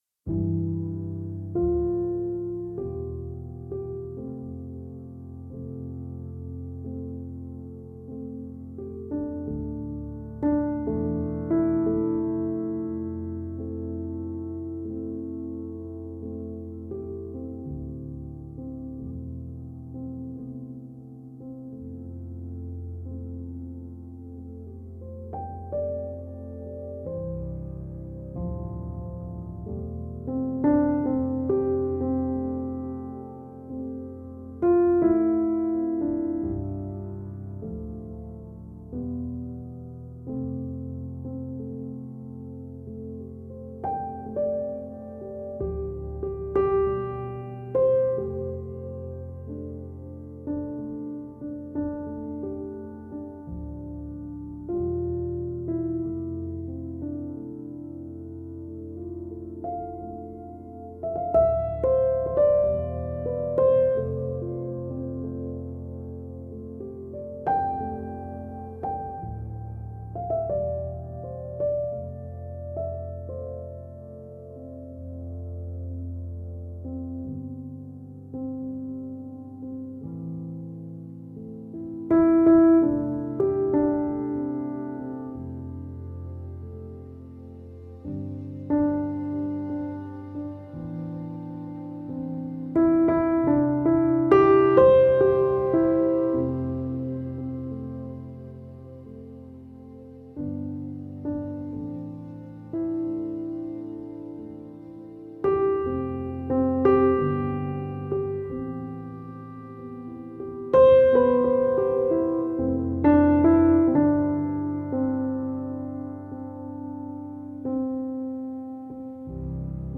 Warm and heartfelt piano theme with thoughtful strings.